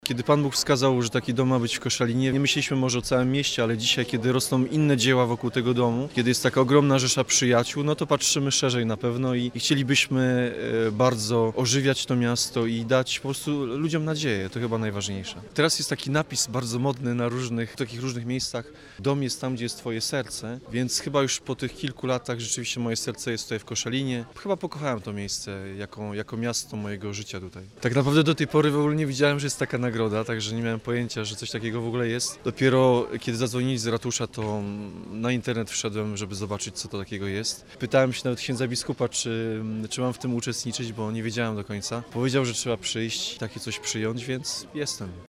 W Koszalinie wręczono doroczne nagrody prezydenta miasta.